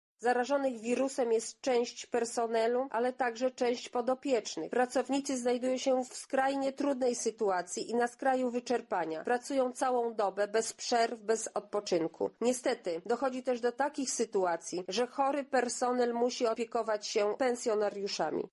Sytuacja w tych domach, gdzie wykryto wirusa jest dramatyczna – mówi poseł Marta Wcisło.